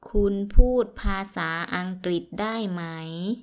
– kunn ∧ puud – paa ∨ saa – angritt ∧ daii ∨ maii